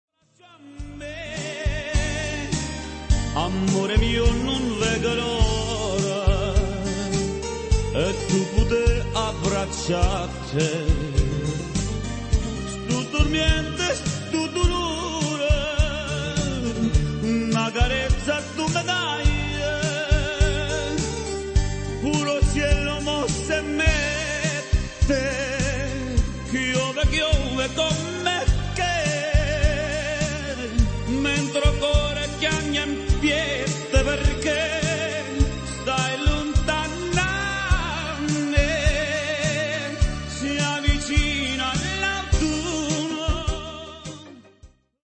lento